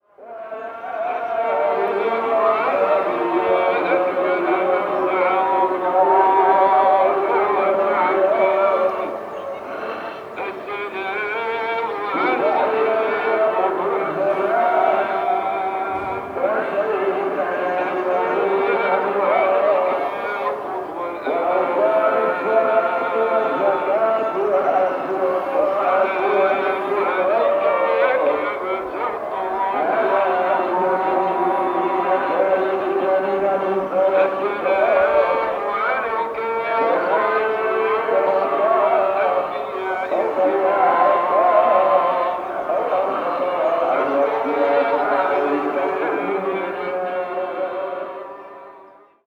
At 6:30am, the call to prayer rung out from various mosques, echoing off the mountainsides, and I recorded it from our hostel roof terrace:
I find the sound to be sinister, but others have said they find it relaxing.
chefchaouen_call_to_prayer.mp3